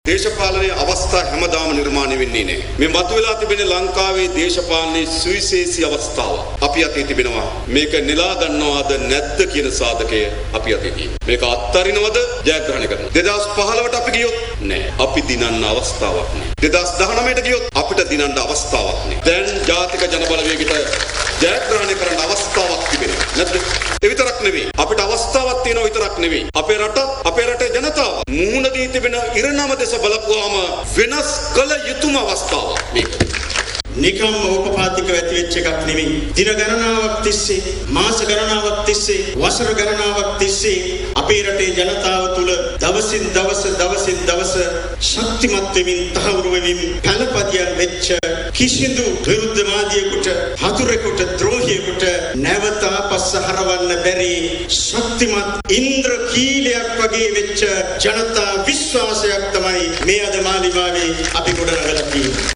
ඒ අනුව වර්තමානයේ මතු වී තිබෙන ලංකාවේ දේශපාලනයේ සුවිශේෂී අවස්ථාව නෙලා ගන්නේද නැද්ද යන්න තීරණය වර්තමානයේ තිබෙන බවයි ගම්පහ ප්‍රදේශයේ පැවති ජන හමුවකට එක් වෙමින් ඔහු සදහන් කළේ.